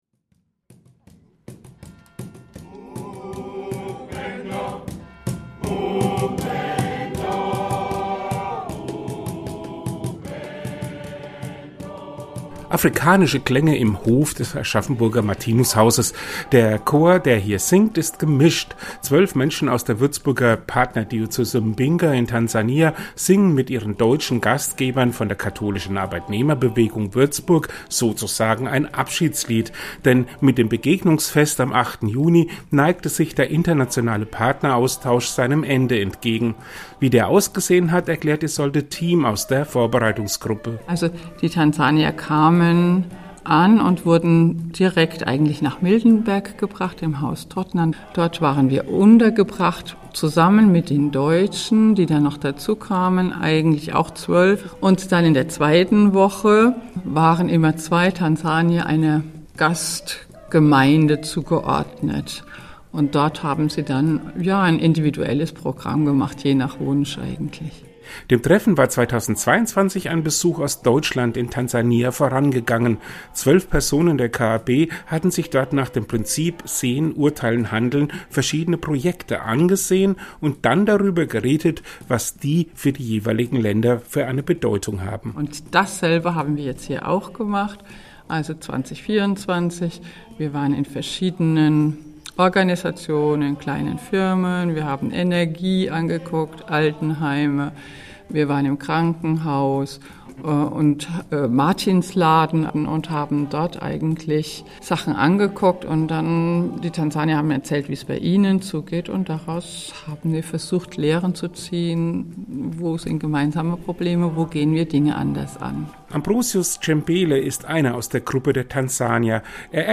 Delegation aus Partnergemeinde Mbinga zu Gast in Aschaffenburg
Geschichten und Berichte aus dem Bistum Würzburg